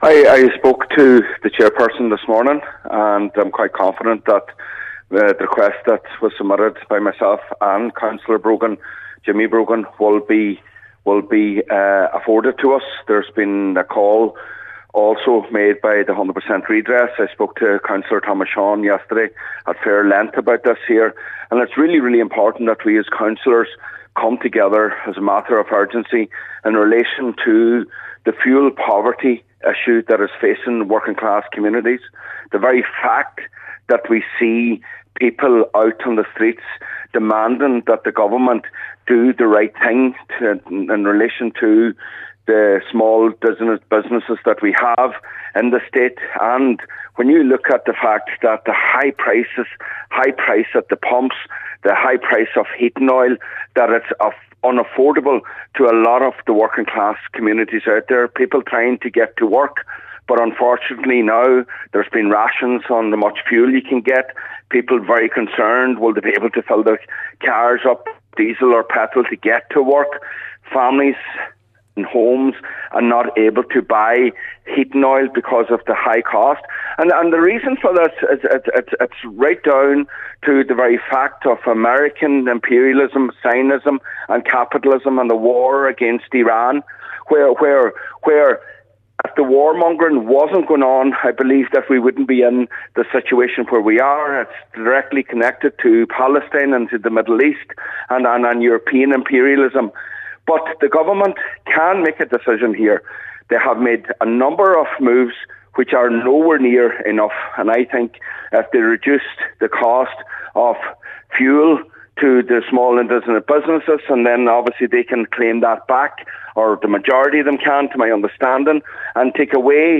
Cllr Mac Giolla Easbuig says he is confident the meeting will be secured: